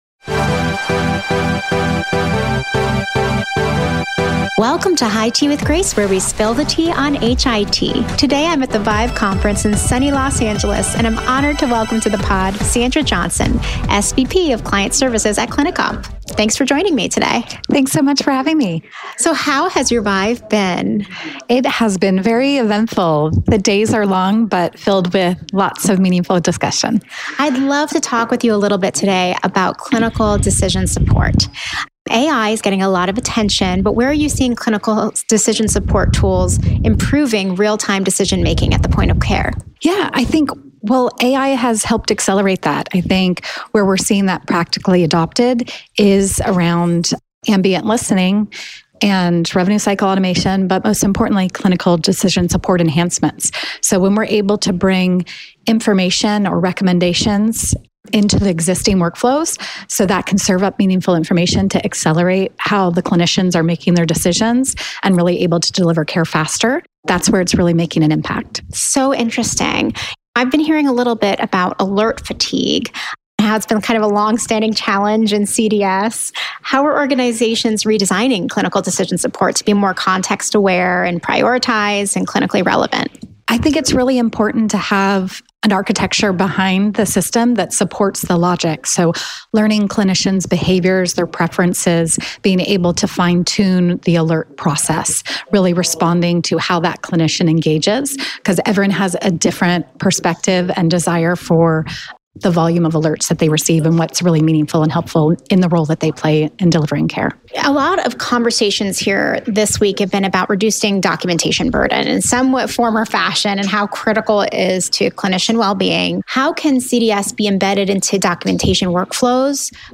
At ViVE this year